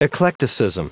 Prononciation du mot eclecticism en anglais (fichier audio)
Prononciation du mot : eclecticism